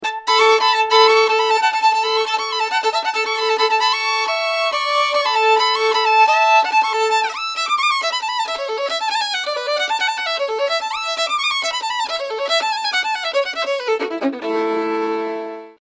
Žánr: Bluegrass.